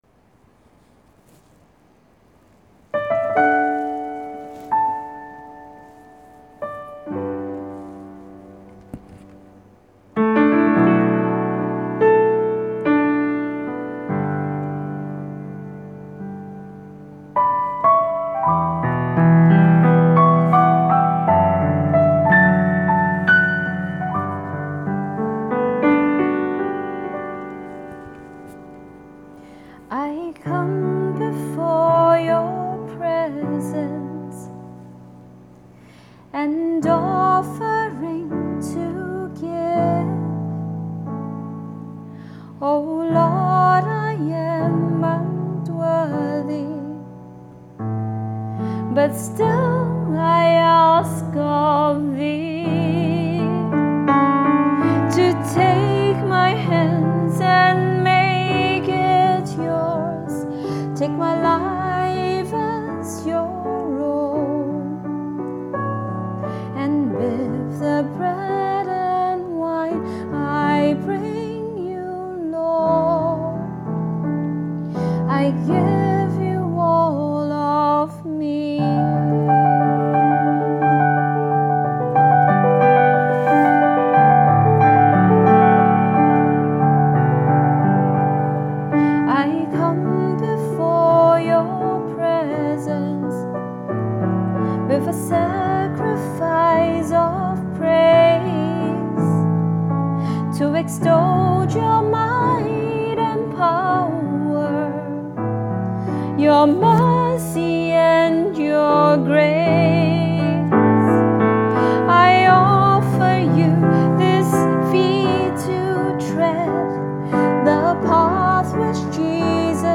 Liturgical: Offertory Song